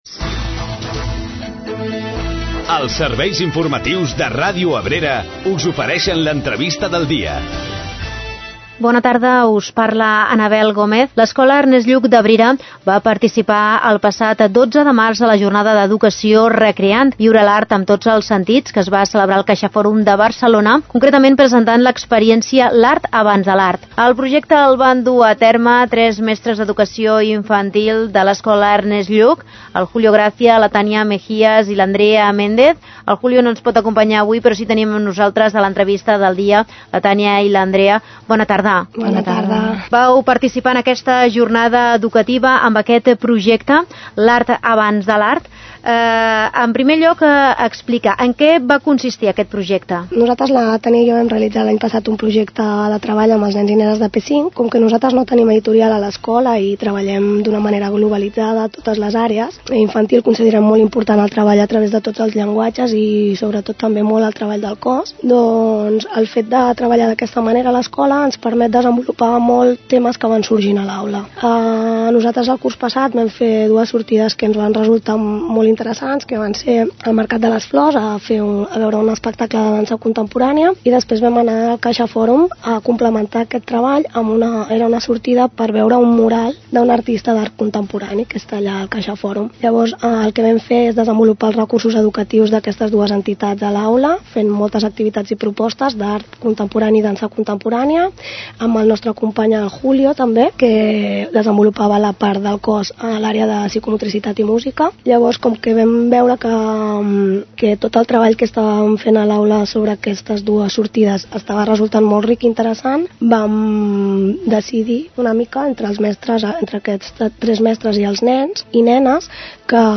Entrevista a Ràdio Abrera i Jornada Recreant.